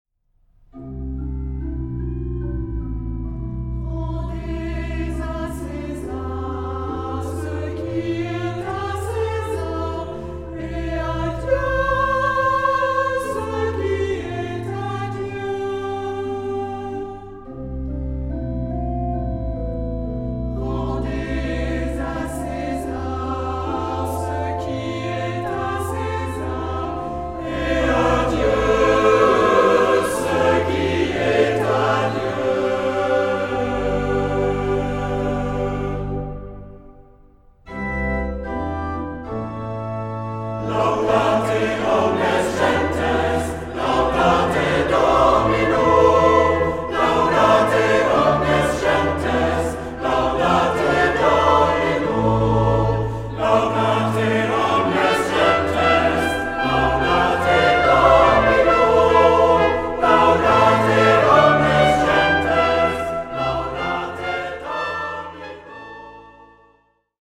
Genre-Style-Form: Sacred ; troparium ; Psalm
Mood of the piece: collected
Type of Choir: SATB  (4 mixed voices )
Instruments: Organ (1) ; Melody instrument (1)
Tonality: C major ; A major